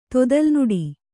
♪ todalnuḍi